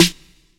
STE_TRP_SNR (1).wav